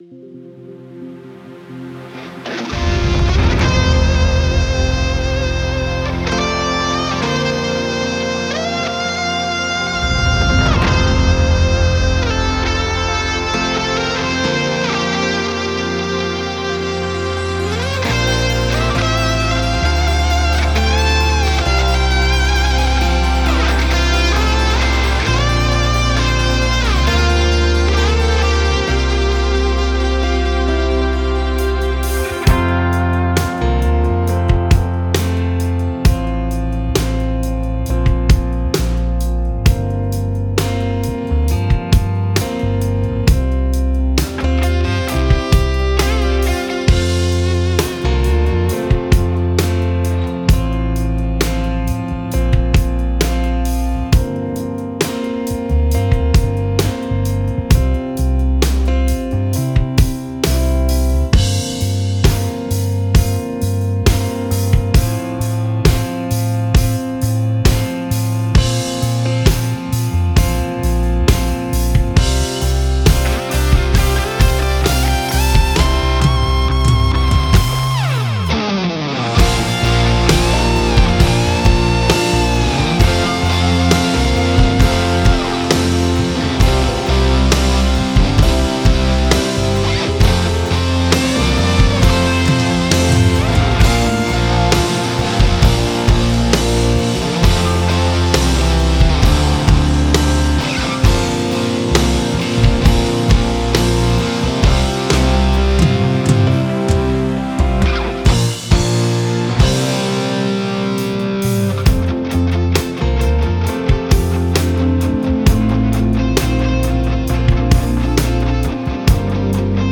Backing track